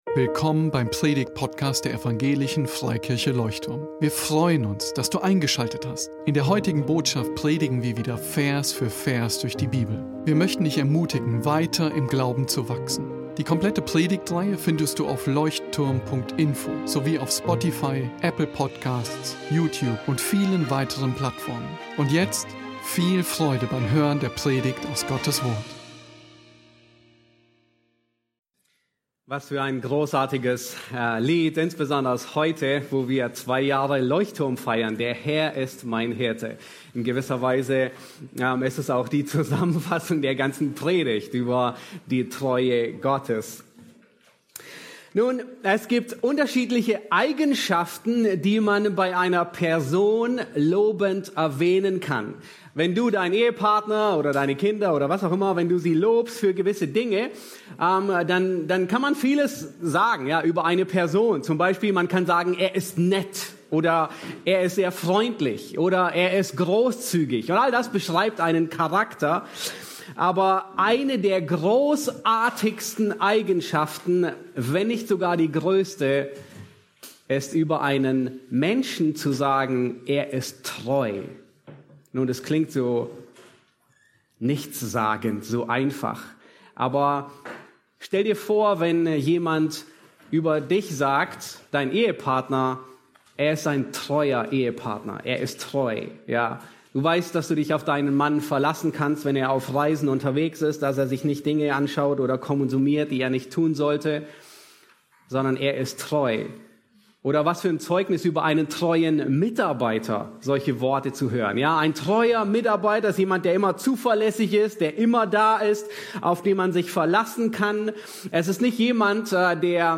Leuchtturm Predigtpodcast Podcast